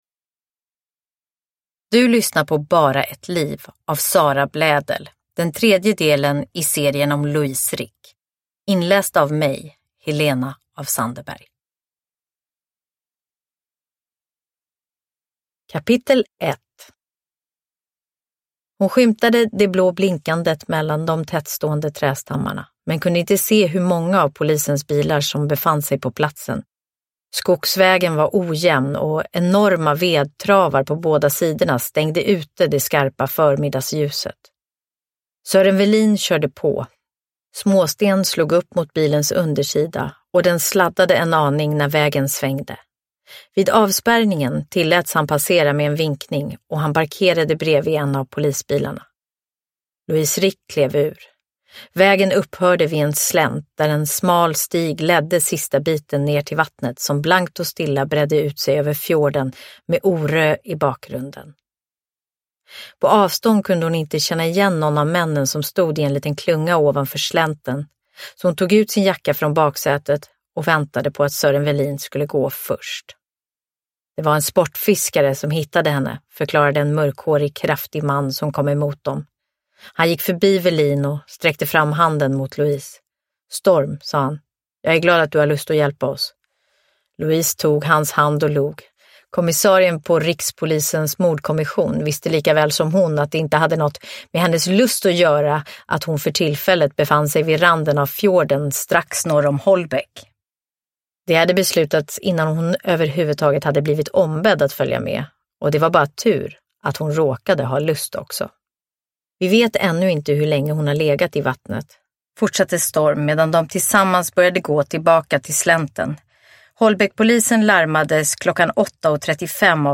Uppläsare: Helena af Sandeberg